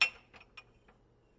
陶瓷打1 " 陶瓷杯4
描述：短命中，陶瓷叮当响
Tag: 陶瓷 叮当声